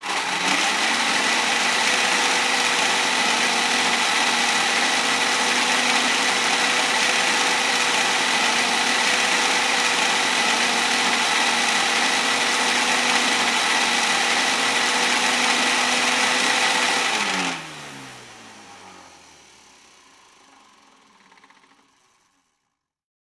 Yard Tools | Sneak On The Lot
Various yard tools start and trim lawn.